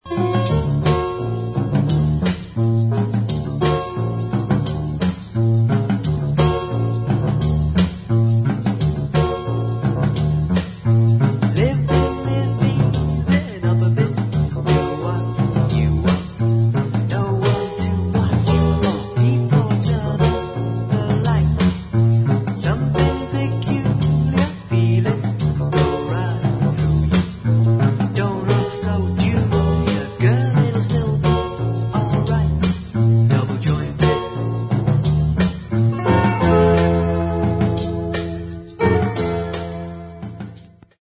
We taped the results as we went along.
Clips (mp3 medium quality stereo)